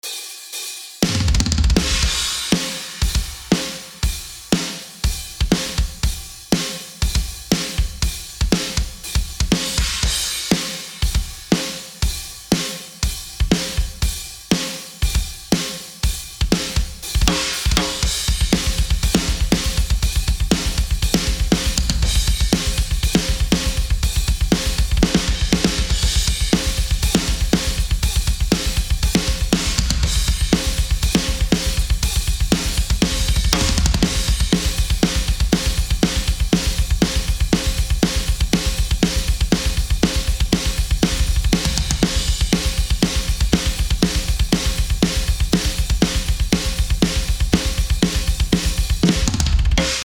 Ohne viel rumgefummel klingt es da z.b. gleich so: